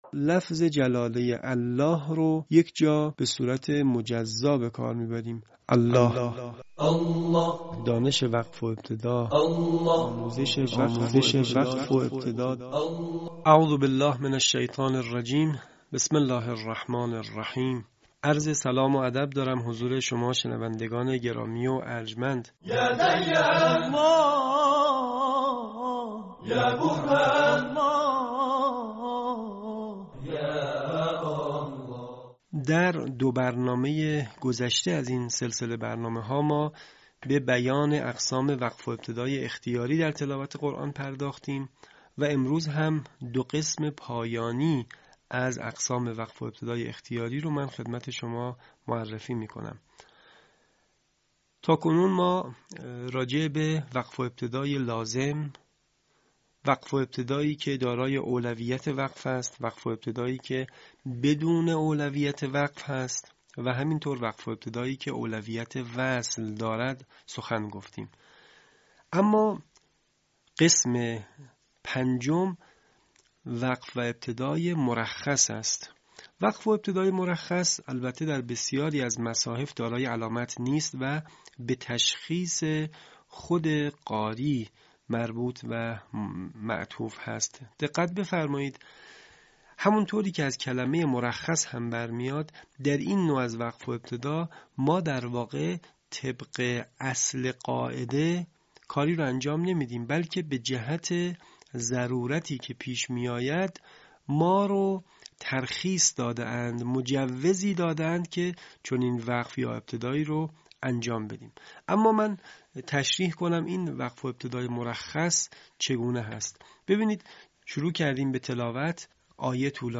صوت | آموزش وقف و ابتدای لازم و مرخص
به همین منظور مجموعه آموزشی شنیداری(صوتی) قرآنی را گردآوری و برای علاقه‌مندان بازنشر می‌کند.